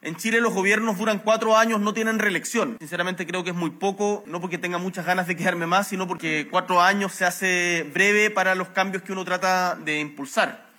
Aunque aclaró que no busca extender su permanencia en La Moneda, sus palabras, durante la inauguración de Enexpro 2025, generaron debate político sobre cuánto podrá cumplir de su programa y reabrieron la discusión sobre el tiempo que tienen los gobiernos para concretar sus reformas.